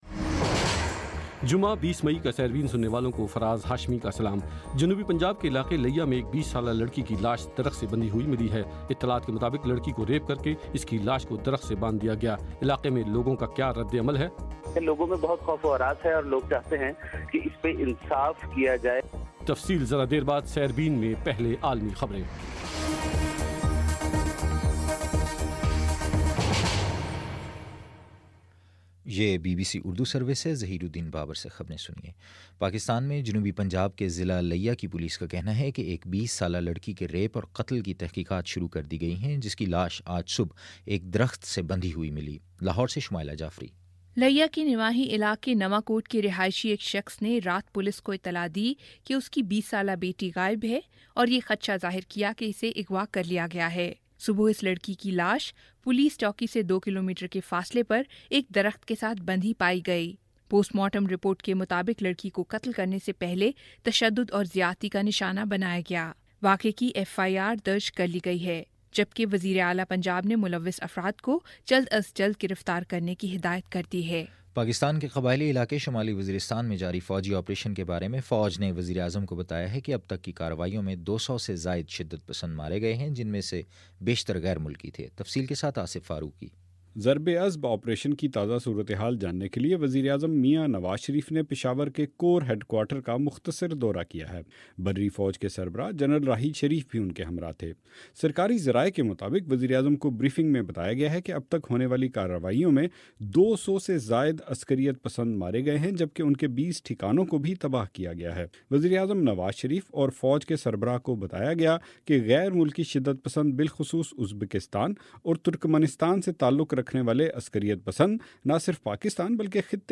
جمعہ 20 جون کا سیربین ریڈیو پروگرام